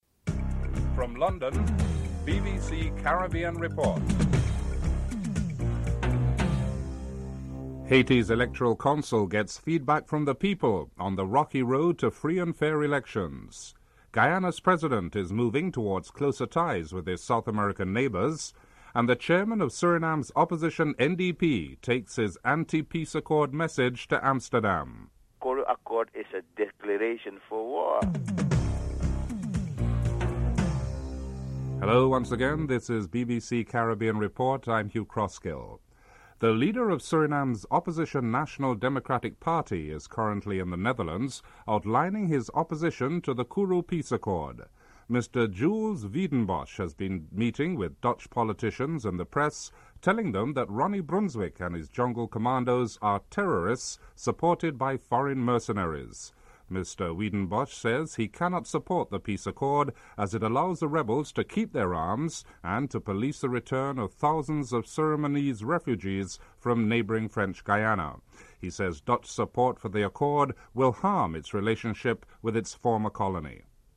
1. Headlines (00:41-01:10)
4. Financial news (09:28-11:16)